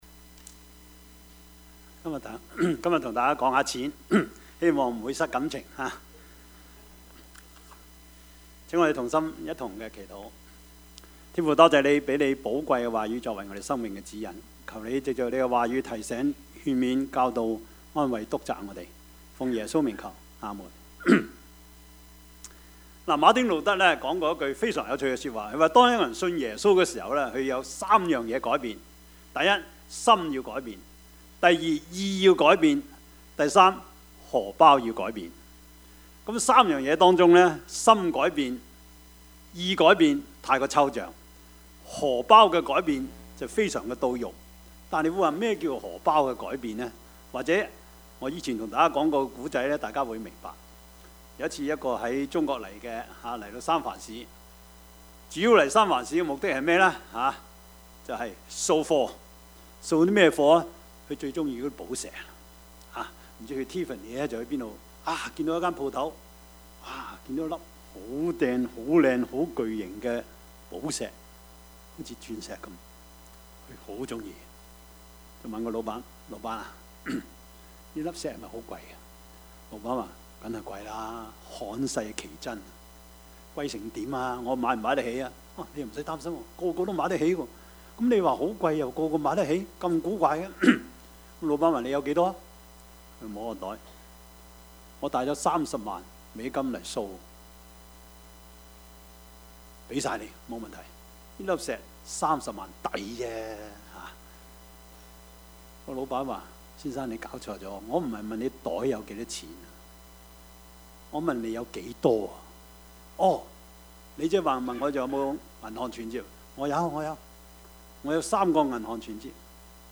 Service Type: 主日崇拜
Topics: 主日證道 « 尋尋覓覓、冷冷清清、淒淒慘慘戚戚！